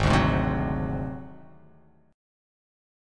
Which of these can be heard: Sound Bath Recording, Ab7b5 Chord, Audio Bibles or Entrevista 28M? Ab7b5 Chord